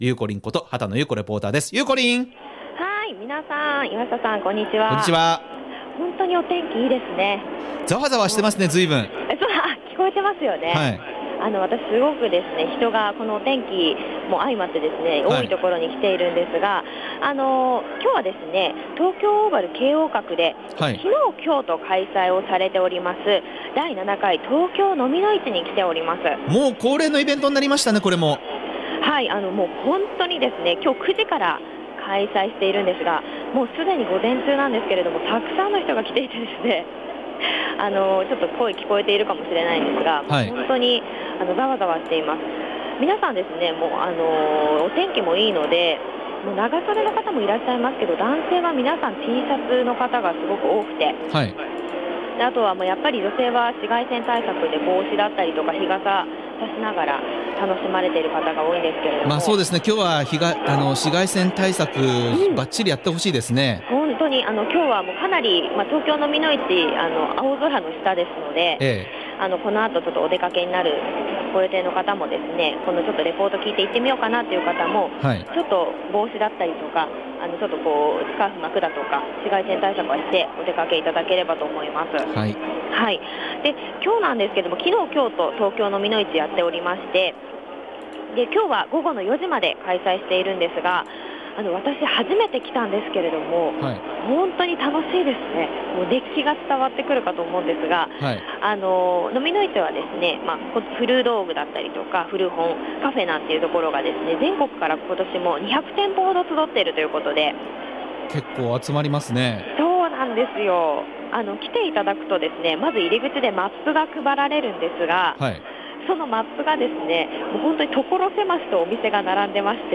母の日の今日は、京王閣で5/9、5/10の2日間開催の「東京蚤の市」に行ってきました〜！